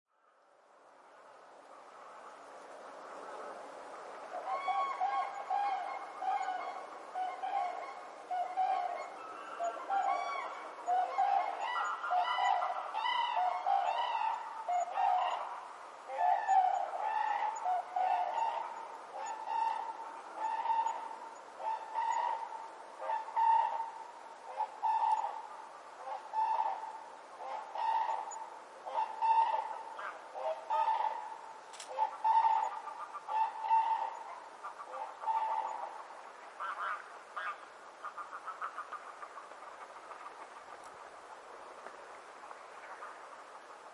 Kranich Ruf 3
Der Ruf des Kranichs ist sehr markant und lässt sich als trompetenähnlich beschreiben. Er klingt tief und laut, fast wie ein „krrruu“.
Kranich-Ruf-Voegel-in-Europa-3.mp3